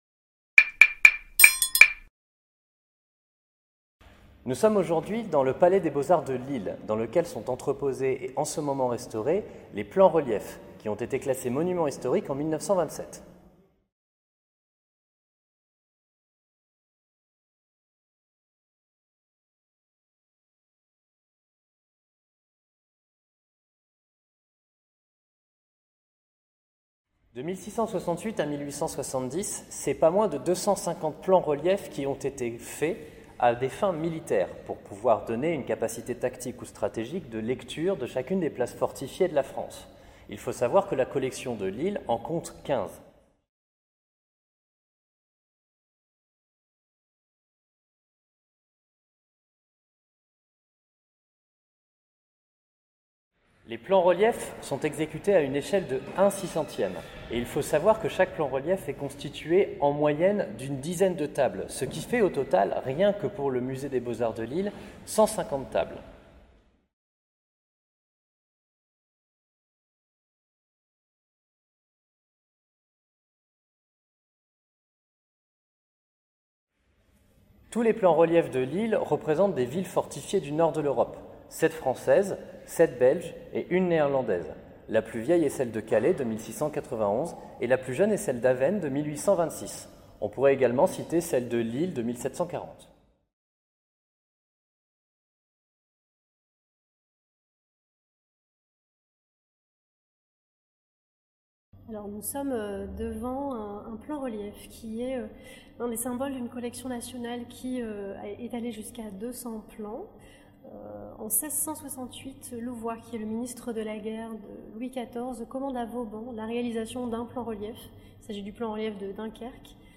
Nous nous sommes déplacés dans la salle de restauration des plans-reliefs au Palais des Beaux-Arts de Lille, qui accueille 15 de ces plans-reliefs (le reste de la collection est aux Invalides); dont le premier historiquement, celui de Dunkerque, commandé par Louvois à Vauban en 1668. 15 plans-reliefs, ce sont en fait 150 tables environ, puisque chaque plan-relief de ville comporte en moyenne 10 tables jointives, qui constituent l'ensemble du plan-relief.